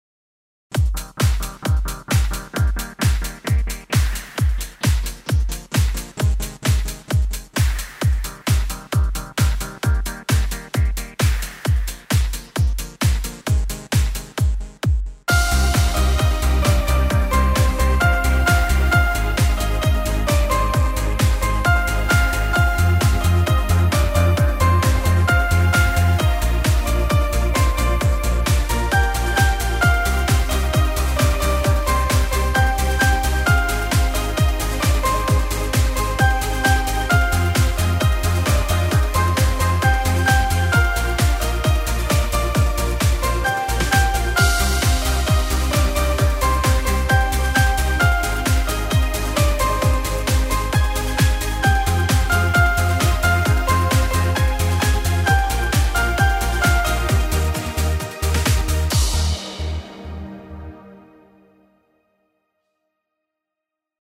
Moderner Schlagerpop mit Synthesizerelementen